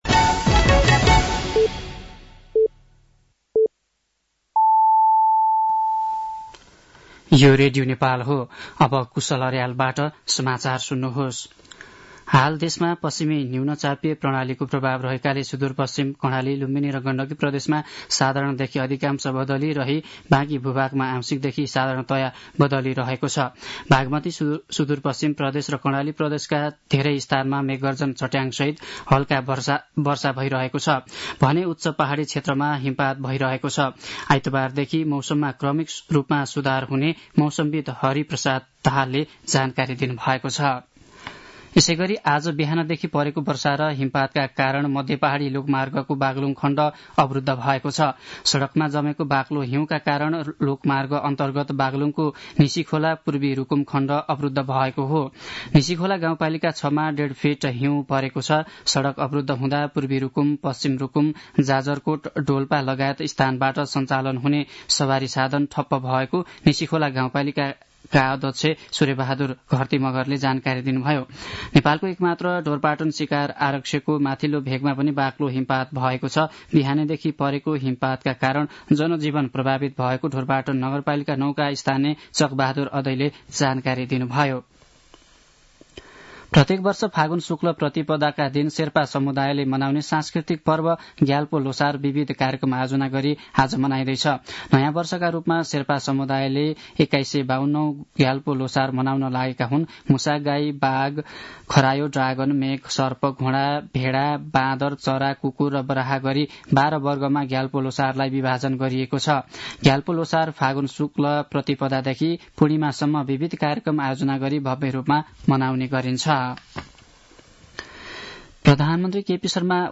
साँझ ५ बजेको नेपाली समाचार : १७ फागुन , २०८१
5-pm-nepali-news-11-16.mp3